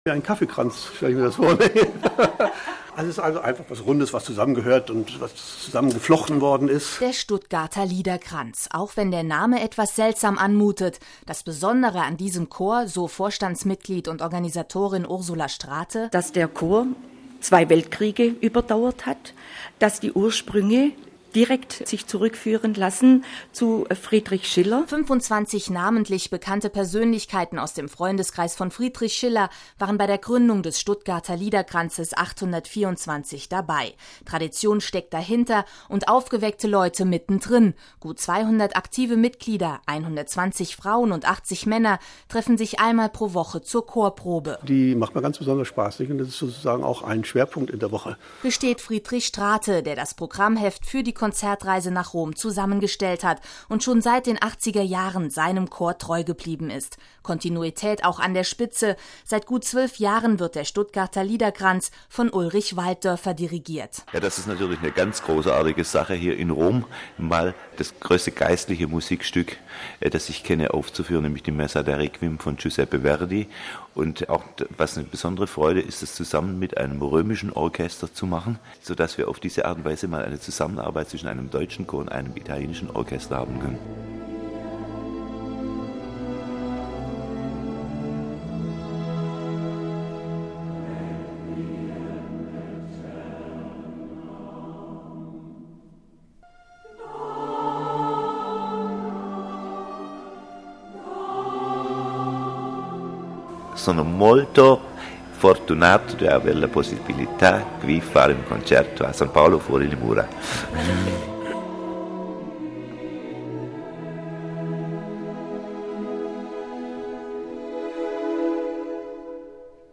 Er gehört zu den traditionsreichsten Chören Deutschlands und gastiert derzeit in Rom. „Messa da Requiem“ von Giuseppe Verdi wird am Samstag in Zusammenarbeit mit dem römischen Symphonieorchester uraufgeführt.